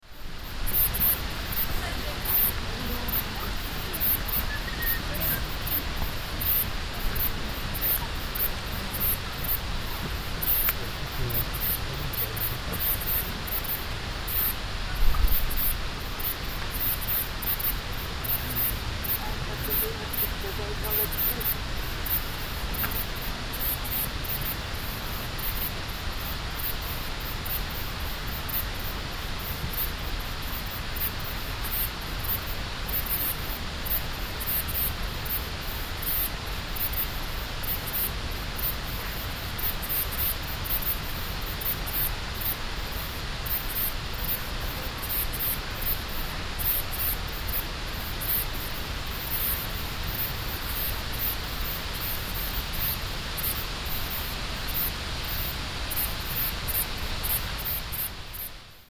Insects singing in the Croatian forest